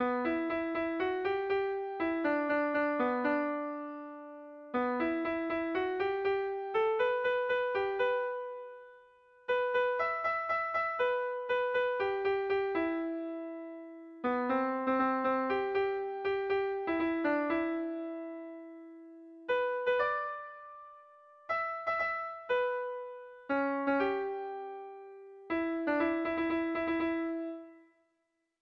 Irrizkoa
Zortziko txikia (hg) / Lau puntuko txikia (ip)
A1A2BD